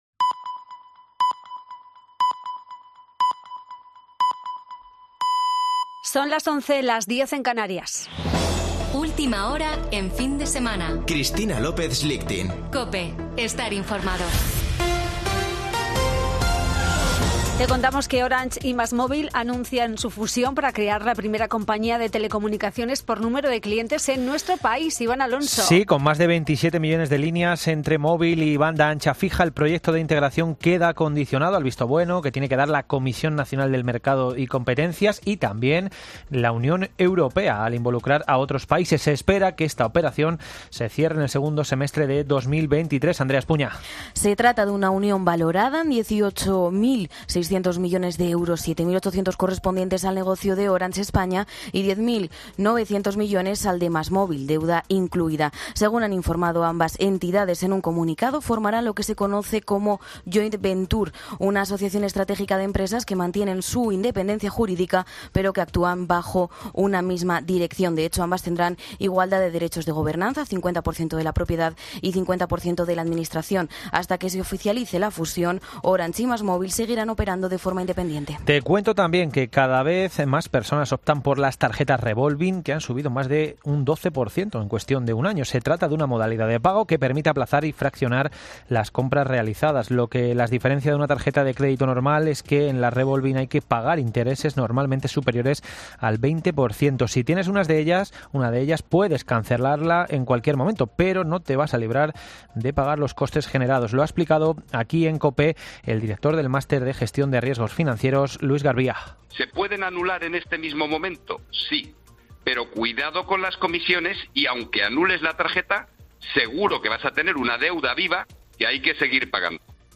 Boletín de noticias de COPE del 23 de julio de 2022 a las 11.00 horas